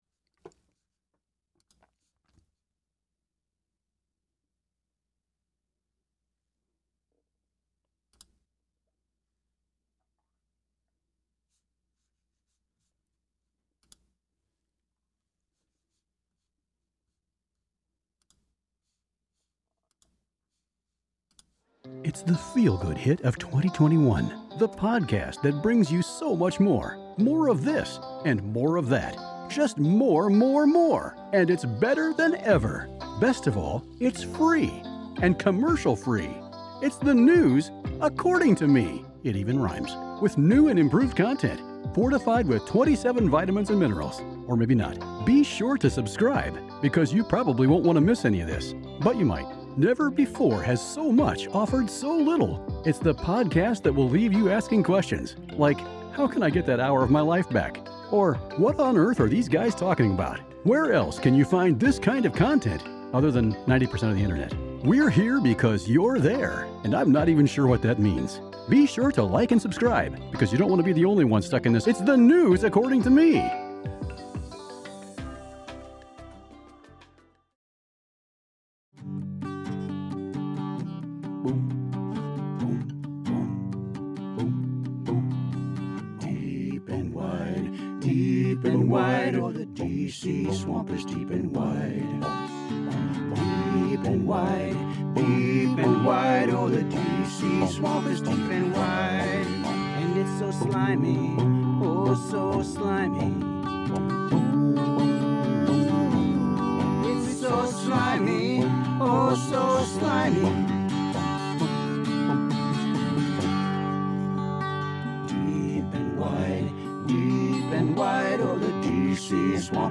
Live Show Today!
Interesting callers from the AI world.